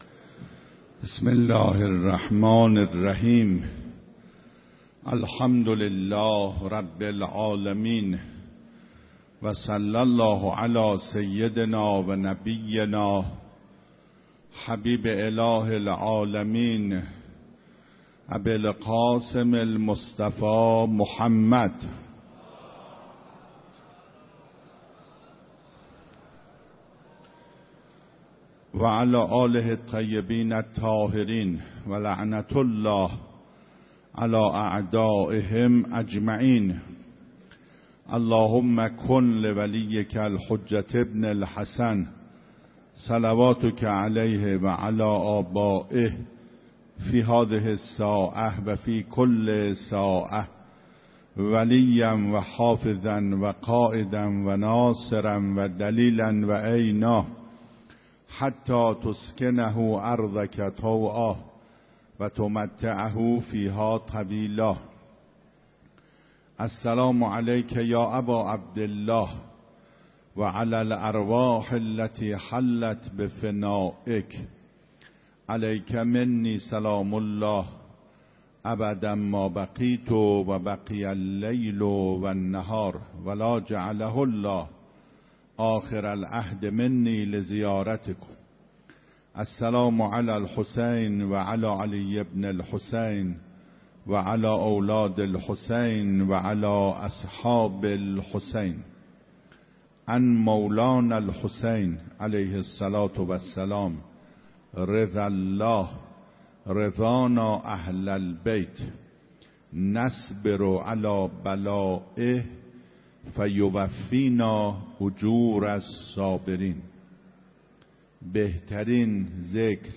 17 مهر 96 - حرم حضرت معصومه - صبر و جایگاه صابران
هفتگی سخنرانی